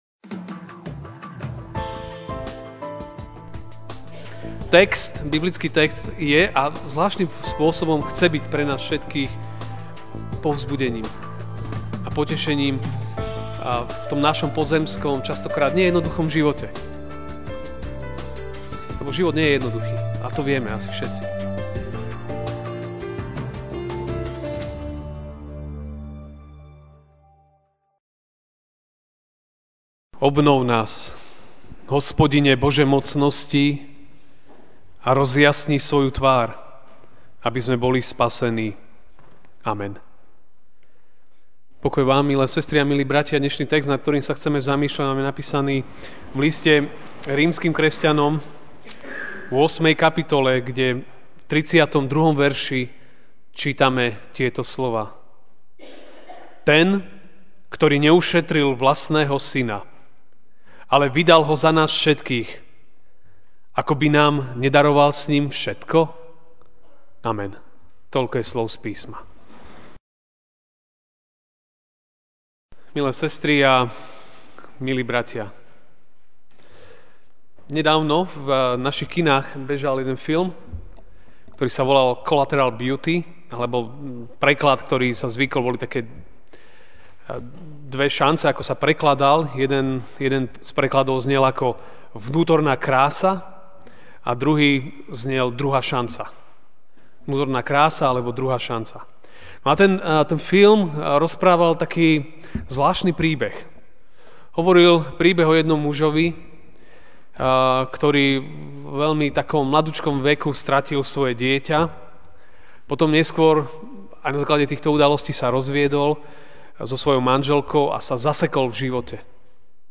MP3 SUBSCRIBE on iTunes(Podcast) Notes Sermons in this Series Ranná kázeň: Ten, ktorý daroval všetko! (Rím. 8, 32) Ten, ktorý neušetril vlastného Syna, ale vydal Ho za nás všetkých, ako by nám nedaroval s Ním všetko?